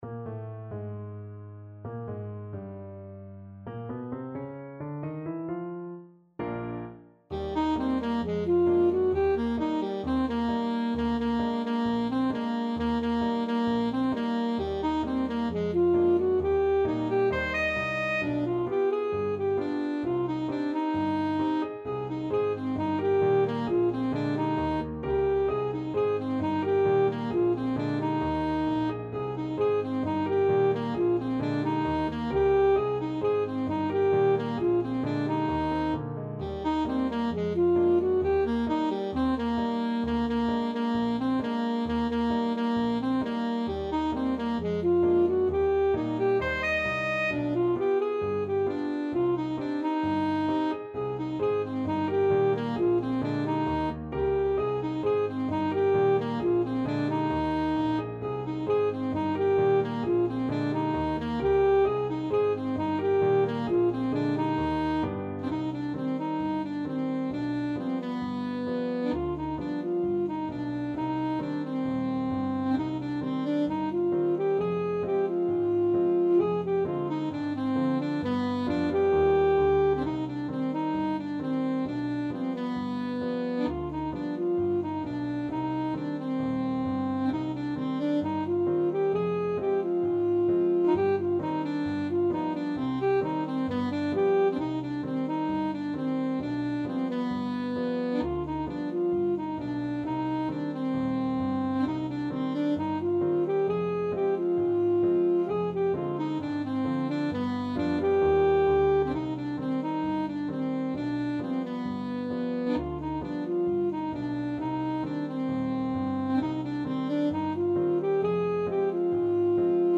Alto Saxophone
Eb major (Sounding Pitch) C major (Alto Saxophone in Eb) (View more Eb major Music for Saxophone )
4/4 (View more 4/4 Music)
= 132 Allegretto ma non troppo
Jazz (View more Jazz Saxophone Music)